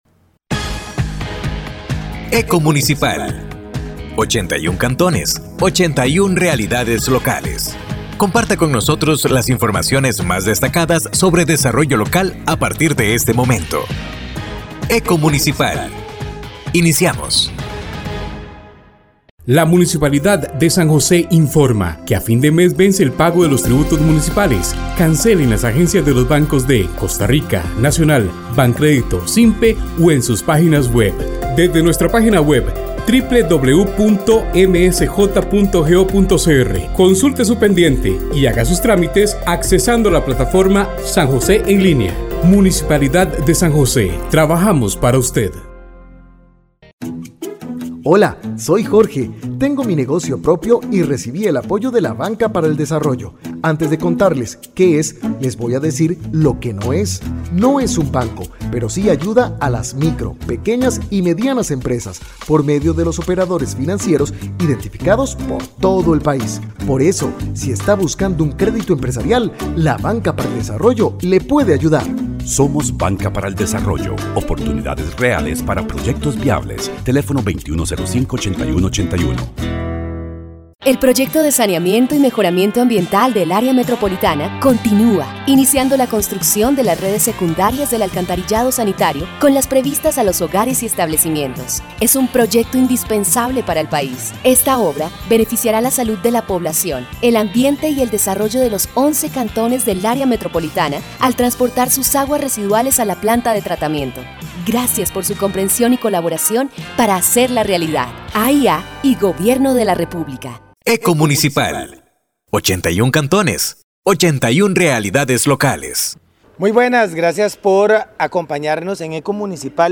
Programa de Radio Eco Municipal